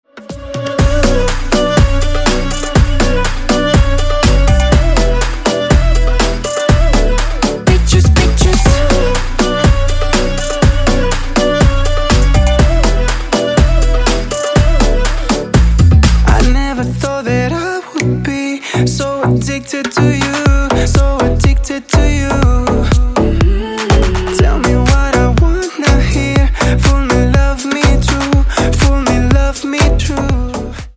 Клубные Рингтоны
Танцевальные Рингтоны